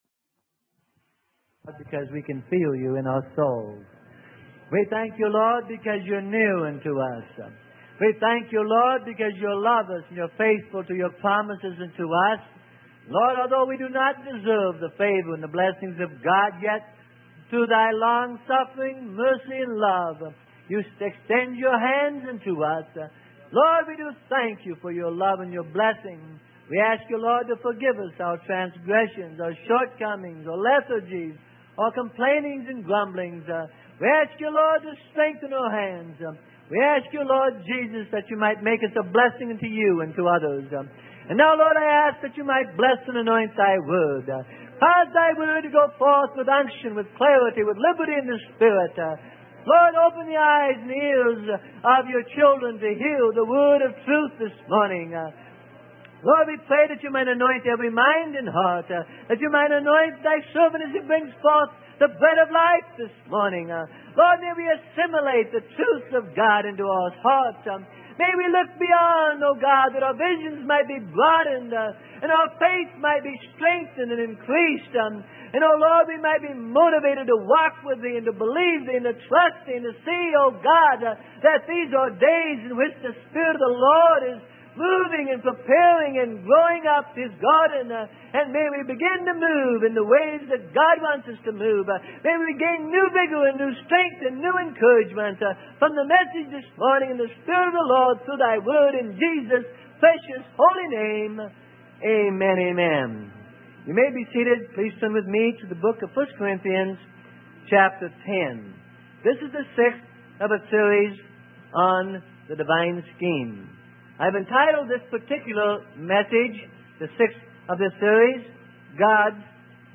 Sermon: God's Divine Scheme - Part 06 - Freely Given Online Library